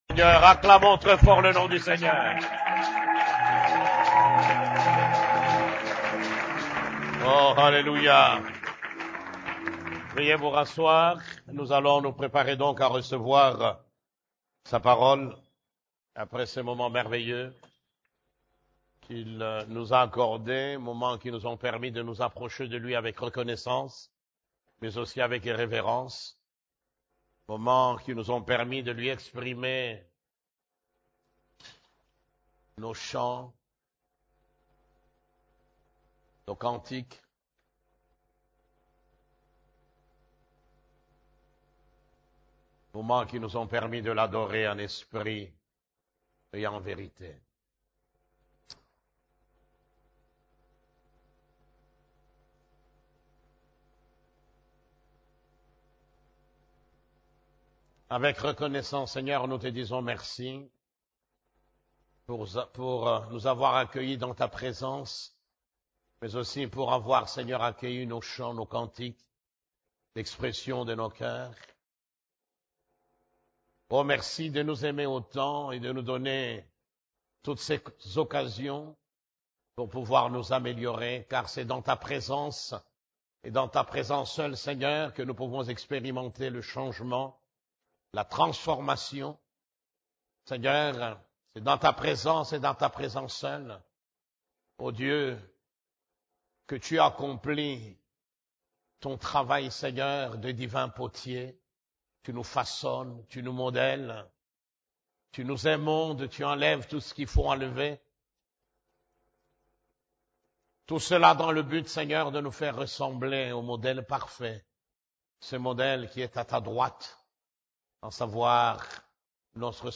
CEF la Borne, Culte du Dimanche, Comment voir l'invisible ? (3)